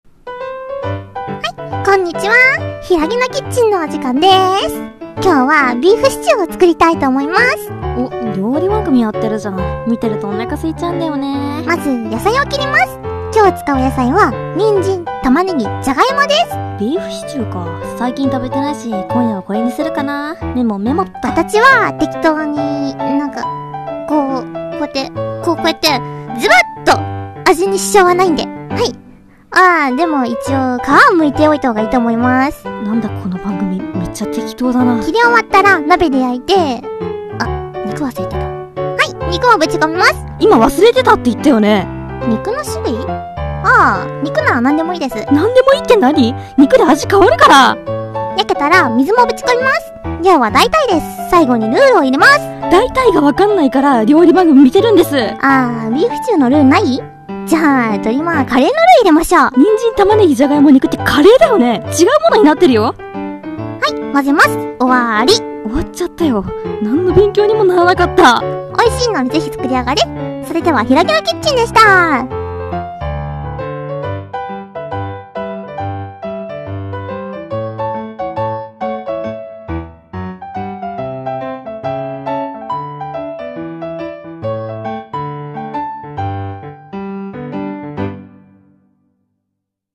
【一人声劇】適当クッキング